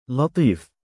母音記号あり：لَطِيف [ laṭīf ] [ ラティーフ ]
male_laTiif.mp3